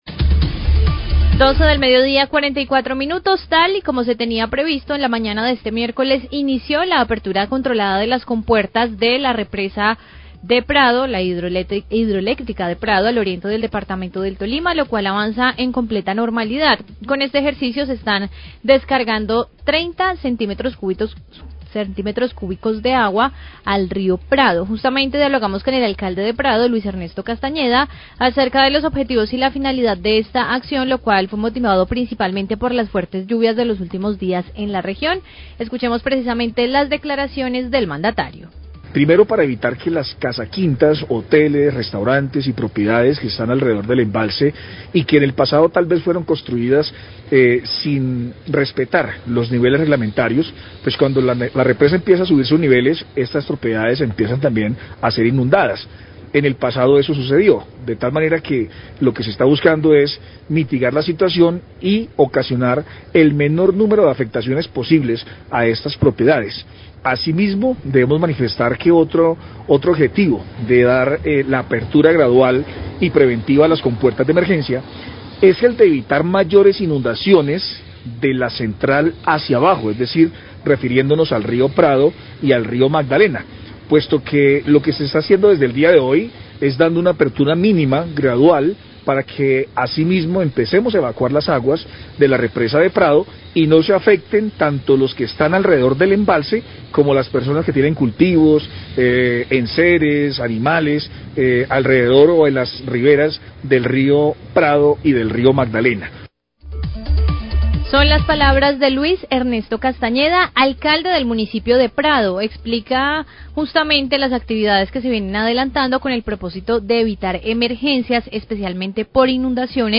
Radio
El Alcalde de Prado,  Luis Ernesto Castañeda, habla de la apertura controlada de las compuertas de emergencia de la Hidroeléctrica del Prado que servirá para controlar inundaciones en viviendas alrededor del embalse y los altos niveles en el Río Prado y el Río Magdalena.